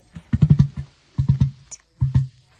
鸸鹋叫声 澳洲鸵鸟雌鸟叫声似敲鼓声